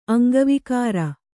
♪ aŋgavikāra